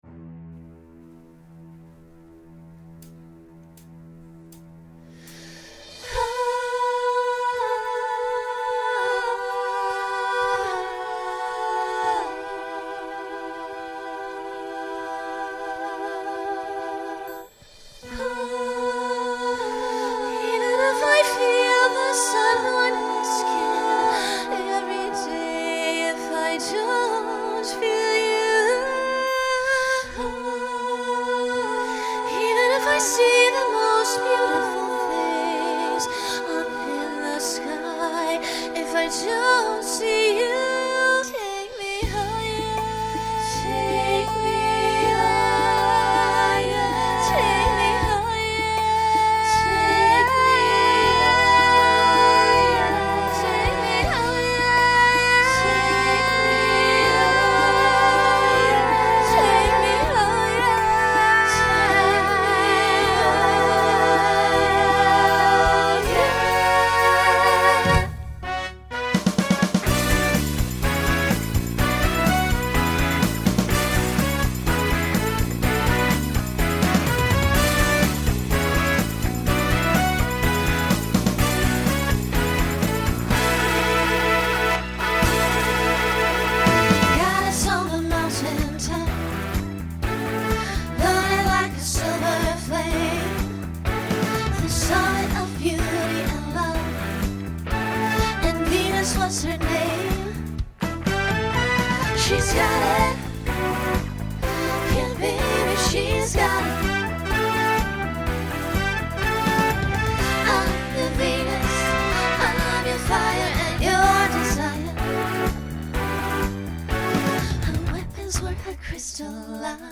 Pop/Dance , Rock
Voicing SSA